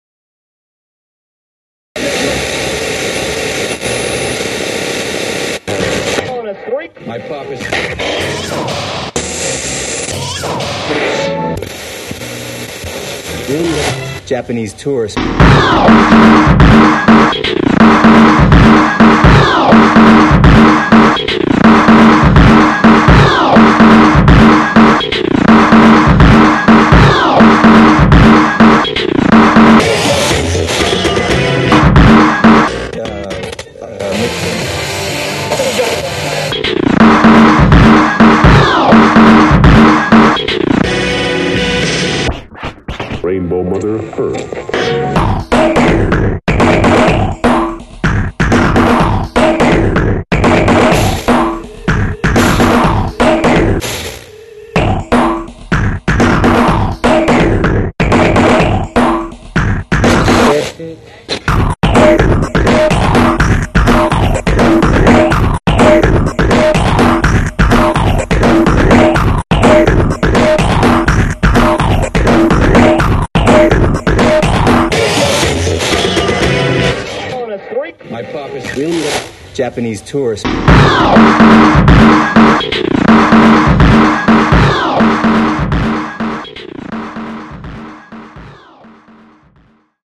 幅広く 『ハウス』 の攻略を試みたオリジナル曲集。